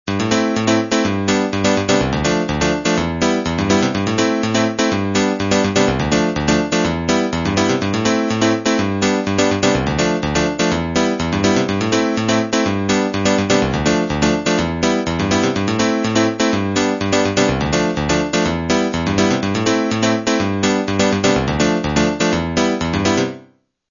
1 channel
stopy_aids_klavir_zdroj.mp3